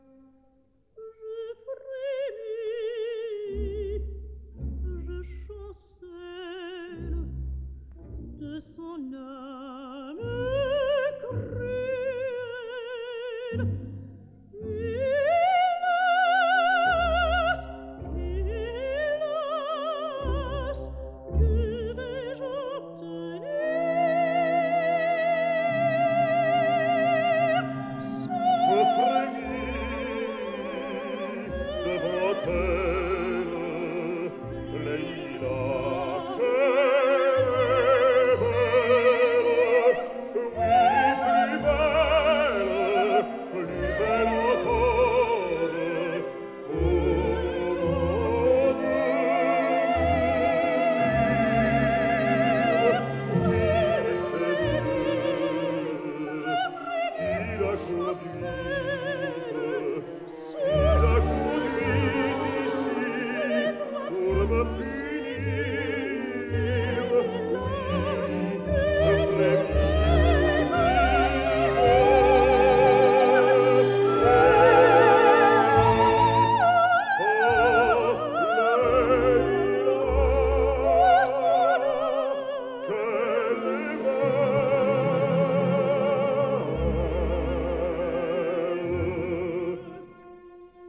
soprano
tenor
baritone
bass